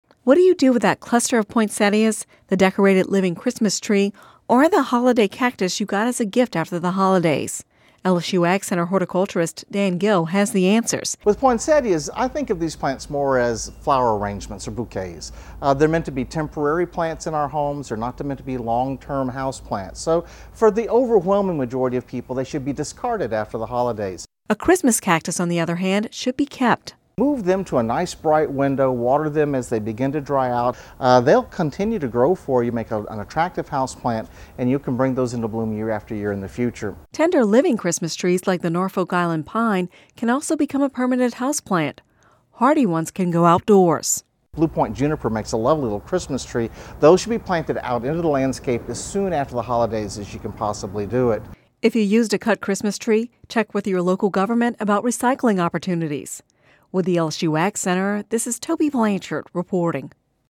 (Radio News 01/03/11) What do you do after the holidays with that cluster of poinsettias, the decorated living Christmas tree or the holiday cactus you got as a gift?